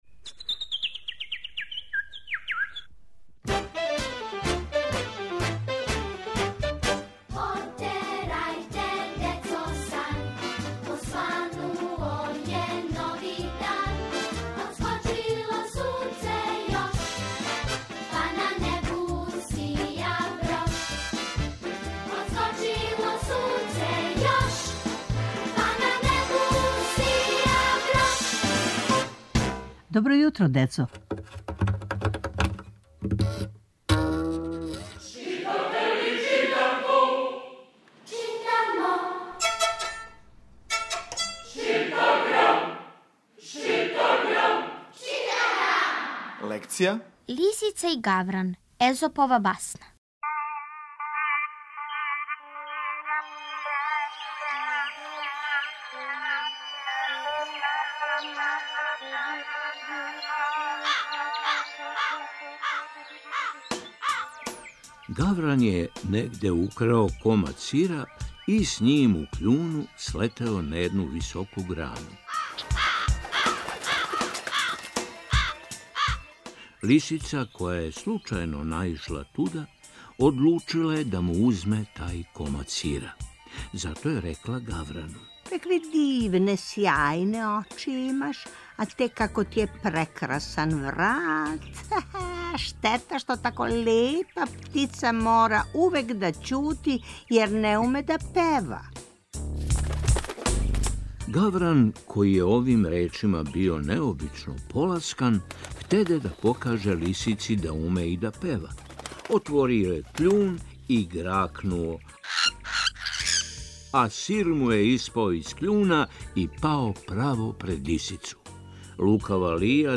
Сваког понедељка у емисији Добро јутро, децо - ЧИТАГРАМ: Читанка за слушање. Ове недеље - први разред, лекција: "Лисица и гавран", Езопова басна